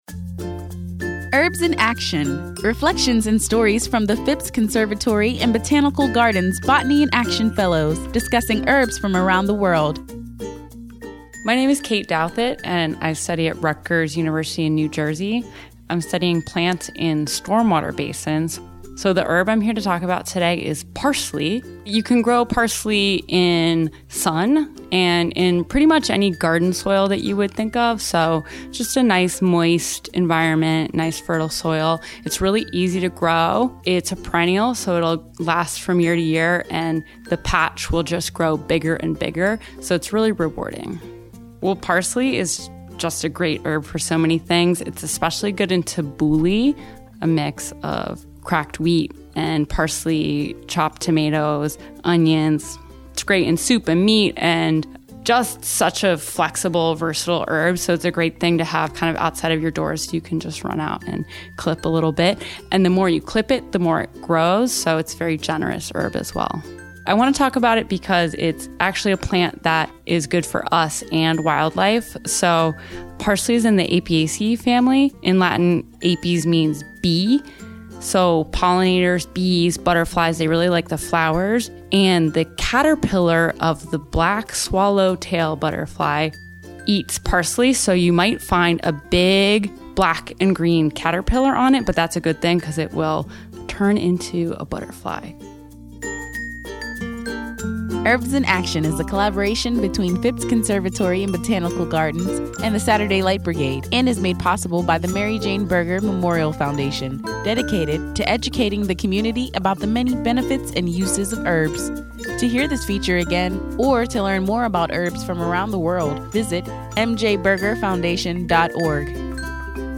Listen as they share their knowledge of and experiences with these herbs as botanists of the world.